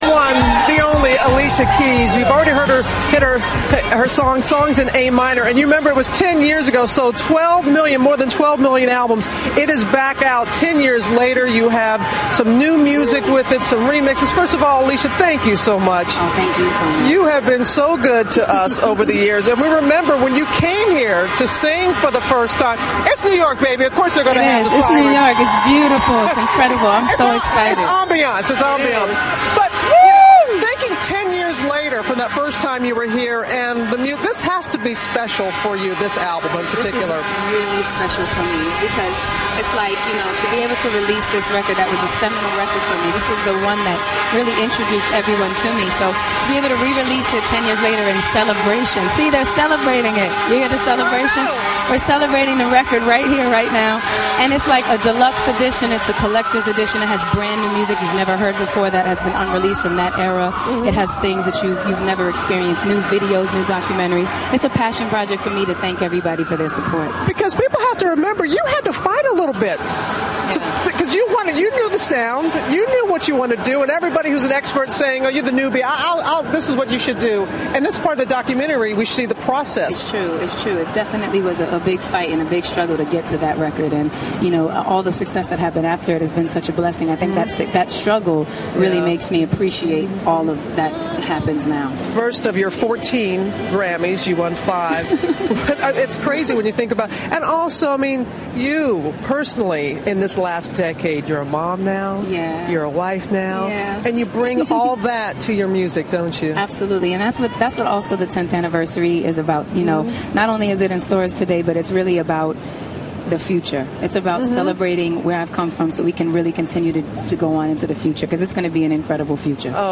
访谈录 2011-06-30&07-02 艾丽西亚·凯斯专访 听力文件下载—在线英语听力室